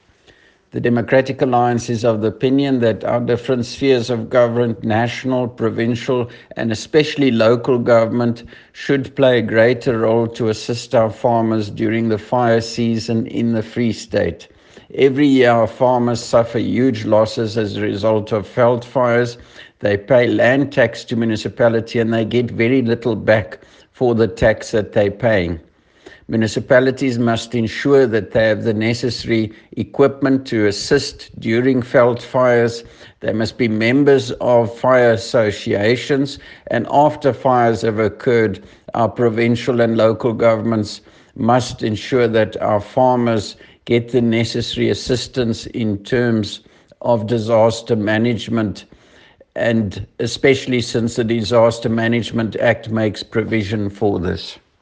Afrikaans soundbites by Dr Roy Jankielsohn MPL.